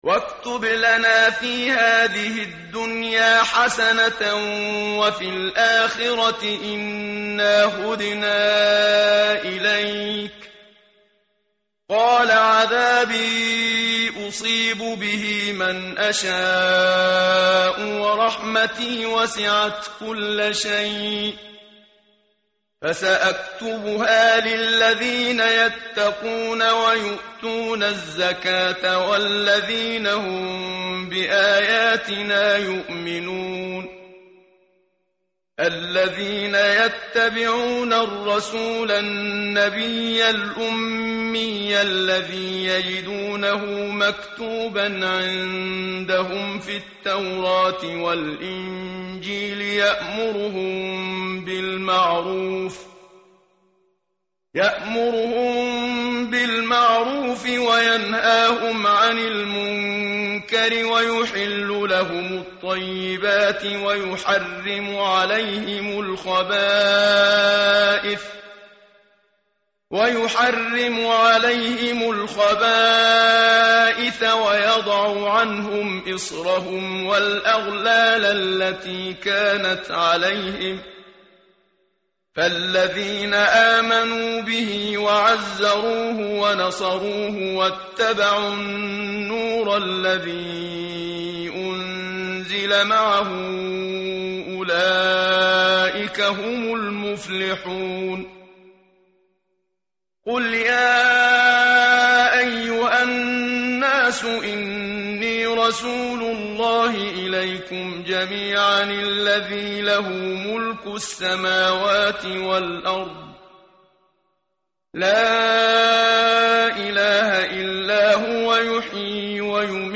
tartil_menshavi_page_170.mp3